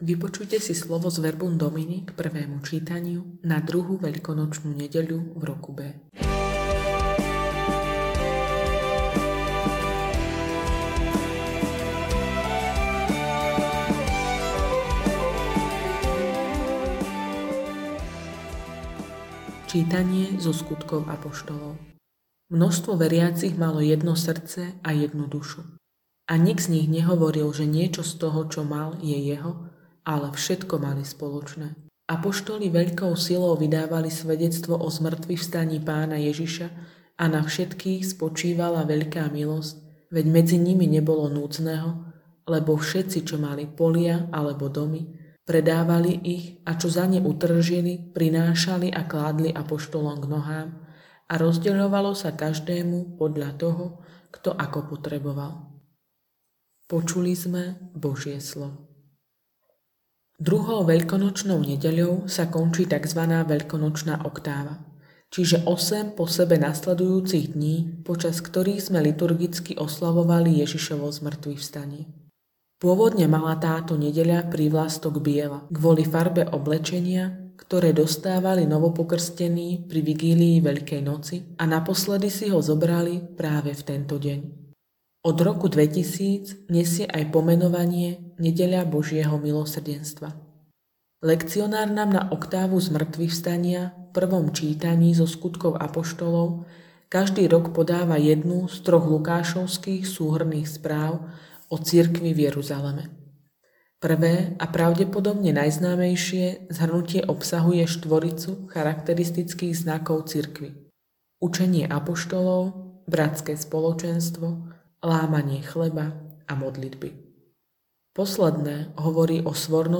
Audionahrávka zamyslenia…
Hudba: BB zbor z Banskobystrickej diecézy – Hymna SDM 2016